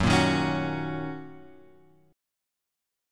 F#6 chord {2 1 1 x 2 2} chord